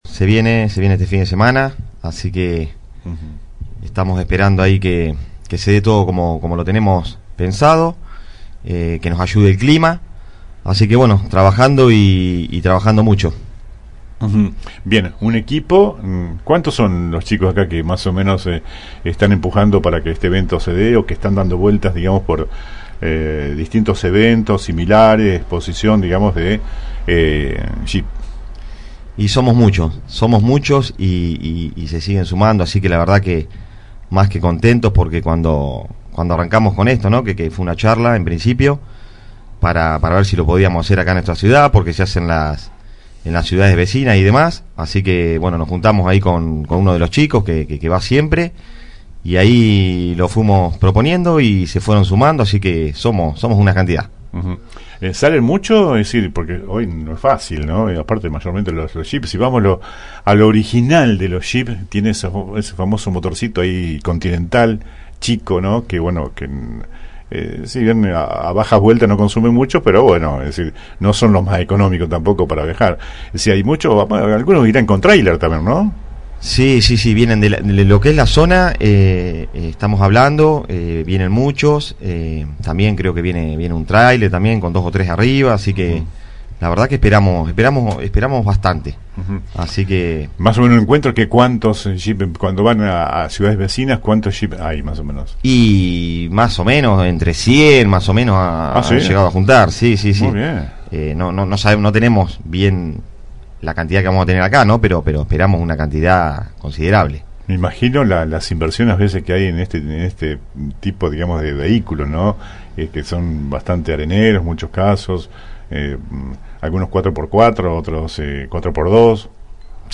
AUDIO COMPLETO DE LA ENTREVISTA